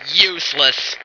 flak_m/sounds/female2/int/F2useless.ogg at ac4c53b3efc011c6eda803d9c1f26cd622afffce